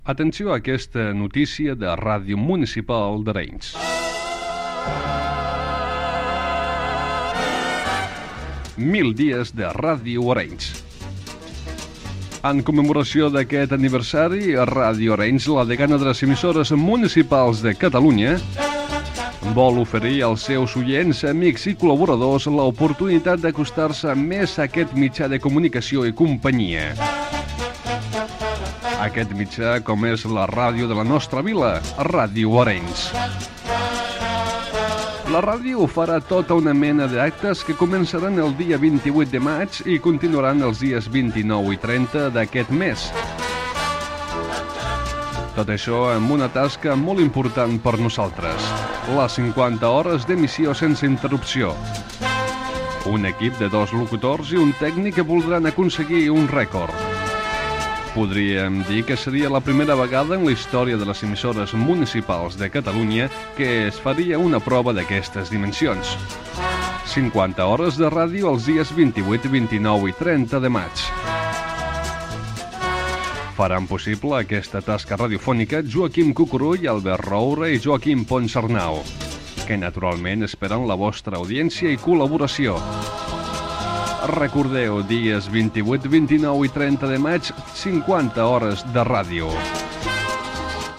Promoció "Mil dies d'emissió".